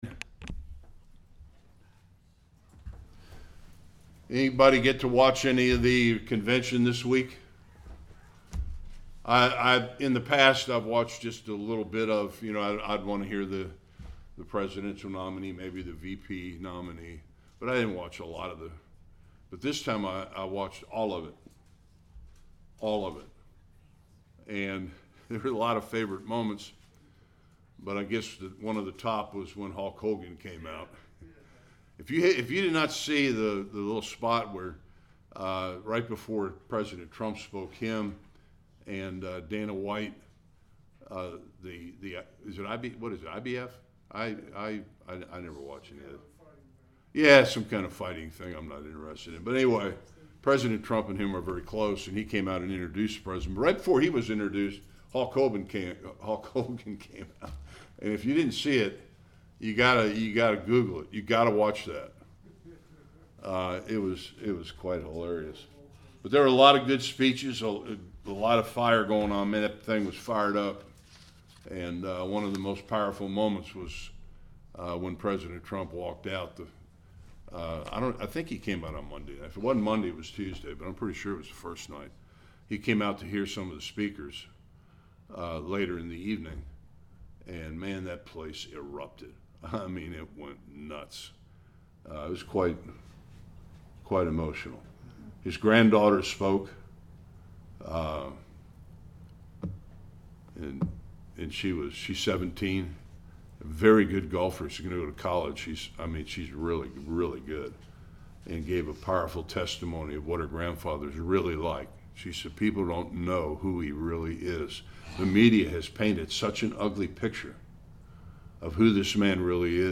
22-57 Service Type: Sunday School King Abimelech and the men of Shechem met their demise for the murder of Gideon’s sons.